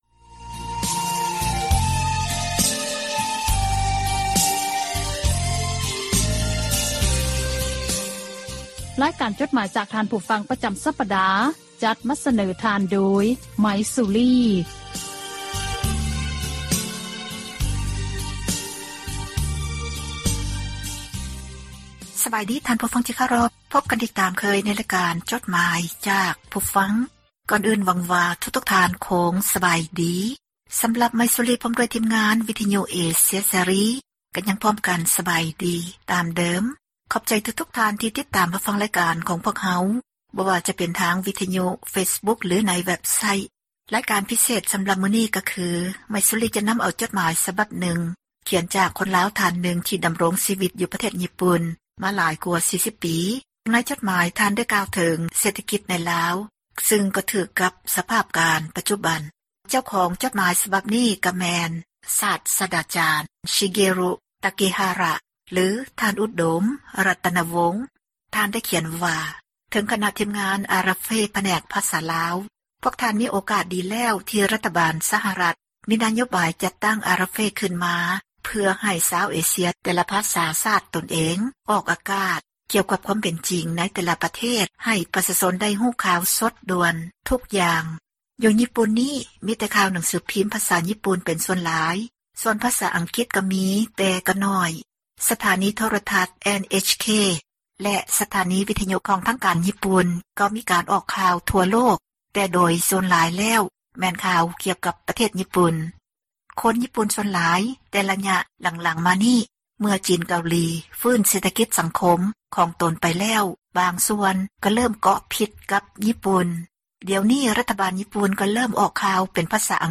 ( ເຊີນທ່ານ ຟັງຣາຍລະອຽດ ຈາກສຽງບັນທຶກໄວ້) ໝາຍເຫດ: ຄວາມຄິດຄວາມເຫັນ ຂອງຜູ່ອ່ານ ທີ່ສະແດງອອກ ໃນເວັບໄຊທ໌ ແລະ ເຟສບຸກຄ໌ ຂອງວິທຍຸ ເອເຊັຽ ເສຣີ, ພວກເຮົາ ທິມງານ ວິທຍຸເອເຊັຽເສຣີ ໃຫ້ຄວາມສຳຄັນ ແລະ ຂອບໃຈ ນຳທຸກໆຖ້ອຍຄຳ, ແລະ ມີໜ້າທີ່ ນຳມາອ່ານໃຫ້ທ່ານ ໄດ້ຮັບຟັງກັນ ແລະ ບໍ່ໄດ້ເສກສັນປັ້ນແຕ່ງໃດໆ, ມີພຽງແຕ່ ປ່ຽນຄຳສັພ ທີ່ບໍ່ສຸພາບ ໃຫ້ເບົາລົງ ເທົ່ານັ້ນ. ດັ່ງນັ້ນ ຂໍໃຫ້ທ່ານຜູ່ຟັງ ຈົ່ງຕັດສິນໃຈເອົາເອງ ວ່າ ຄວາມຄິດເຫັນນັ້ນ ເປັນໜ້າເຊື່ອຖື ແລະ ຄວາມຈິງ ຫລາຍ-ໜ້ອຍ ປານໃດ.